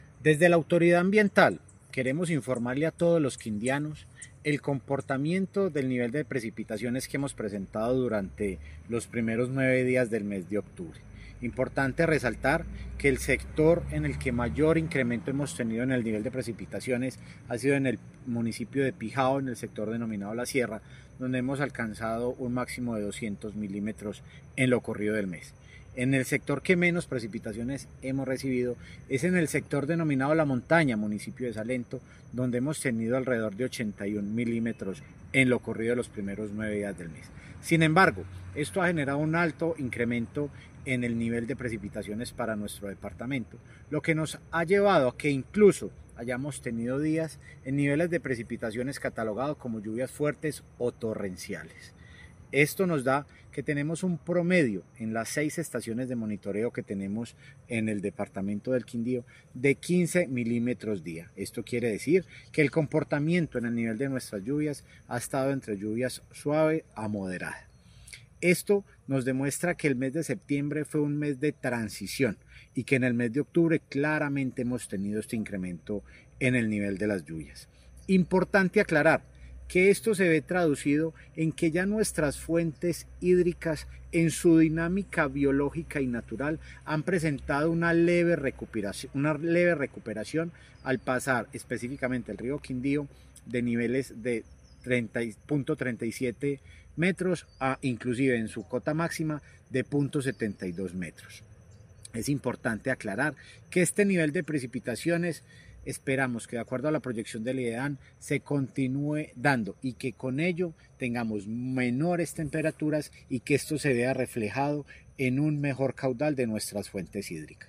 AUDIO-DIRECTOR-CRQ-REPORTE-LLUVIAS-.mp3